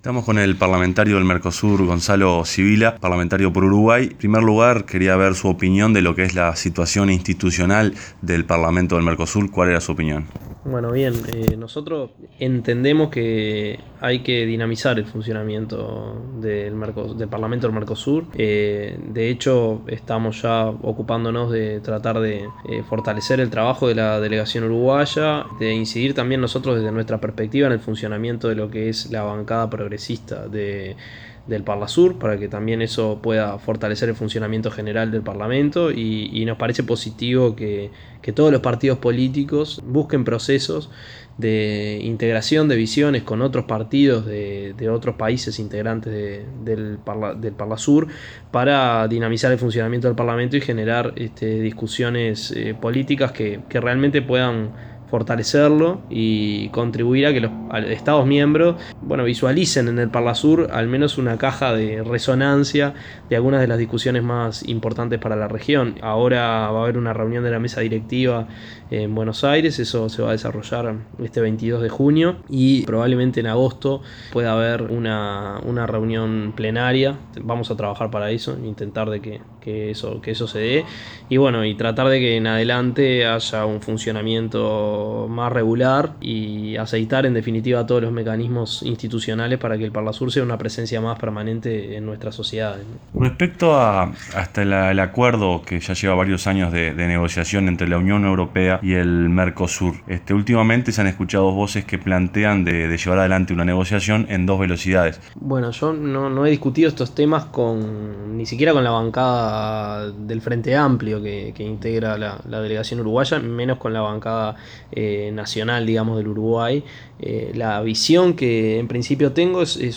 Gonzalo Civila, joven diputado uruguayo por el Partido Socialista - Frente Amplio y Parlamentario del MERCOSUR, fue entrevistado por Radio PARLASUR.